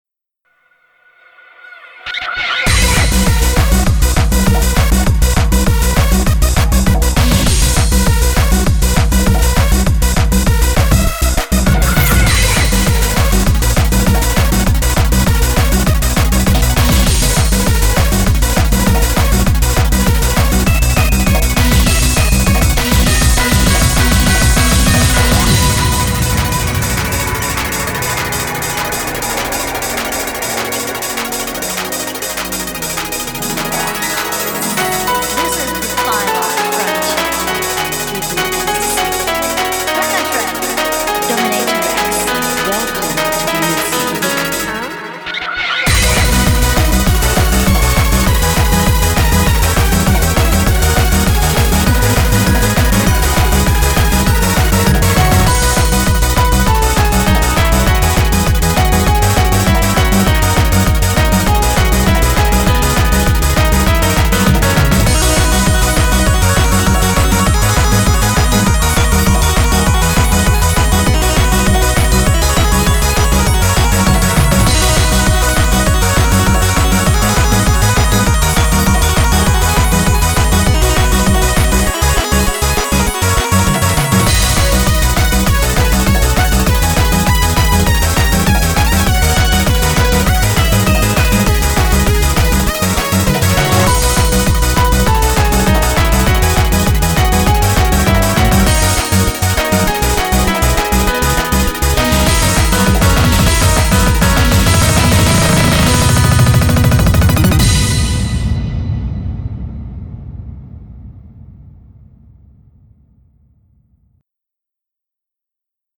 BPM200